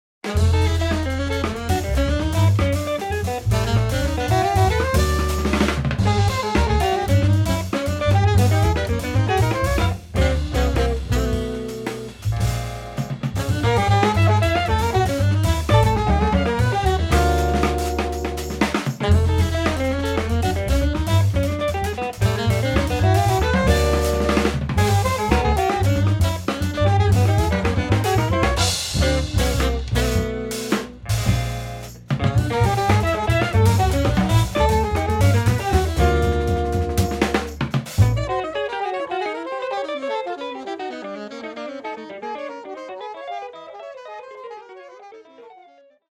guitar
sax, flute
bass
drums